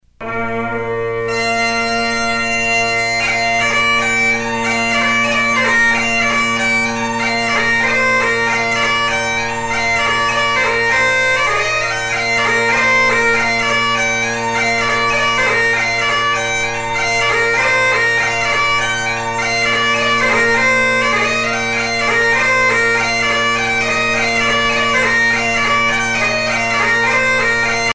Highland bagpipes
Pièce musicale éditée